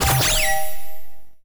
sci-fi_power_up_09.wav